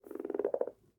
sounds / mob / frog / idle5.ogg